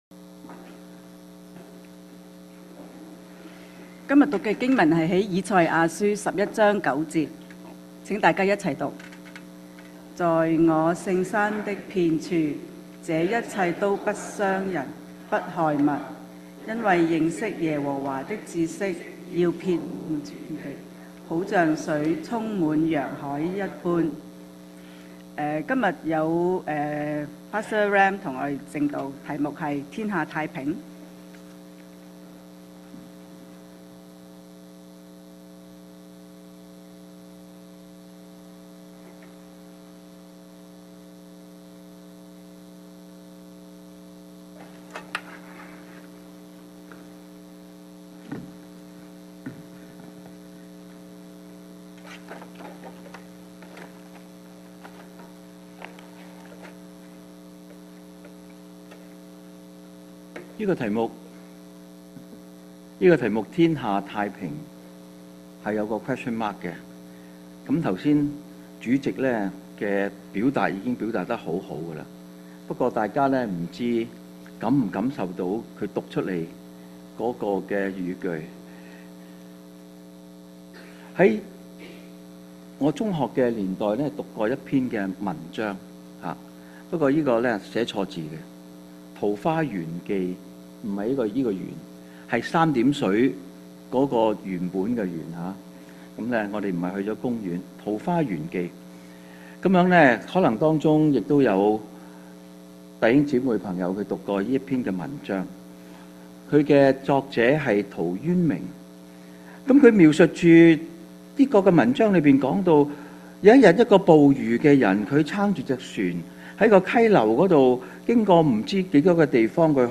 講道錄像 信息:《天下太平》 經文